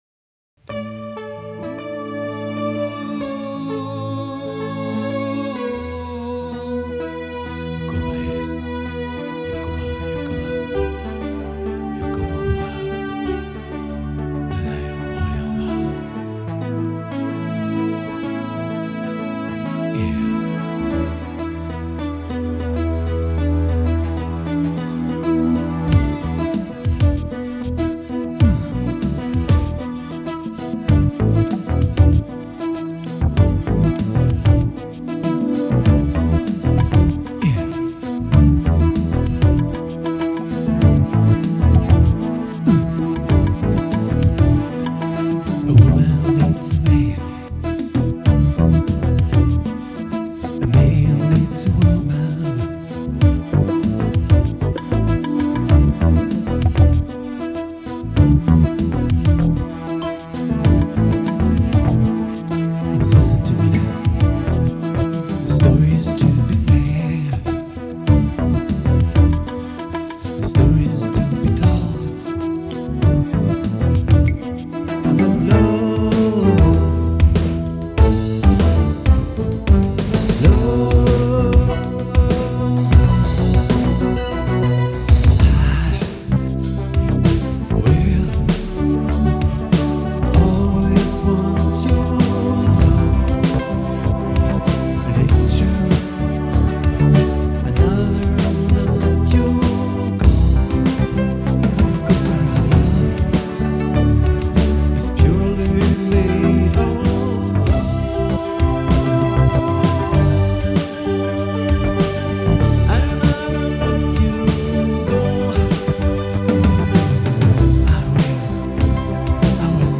musik, sång och produktion.